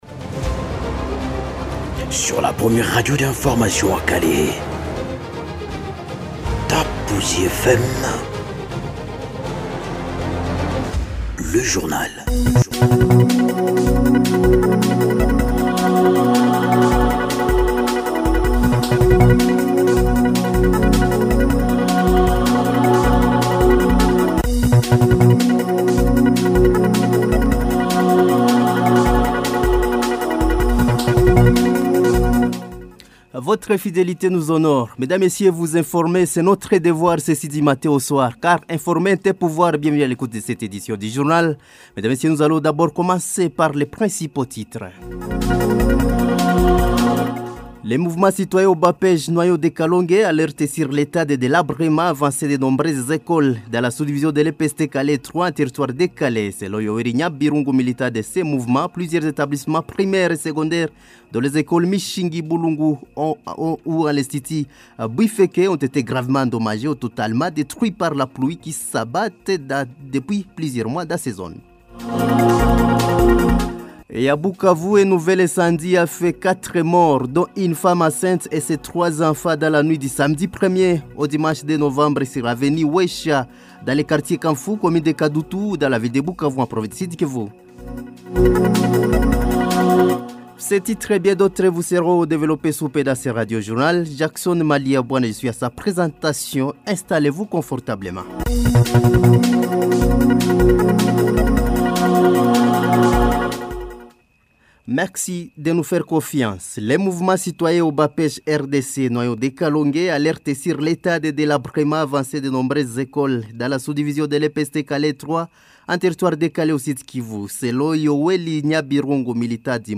Journal soir ce lundi 03 novembre sur la radio Top Buzi 93.5 MHz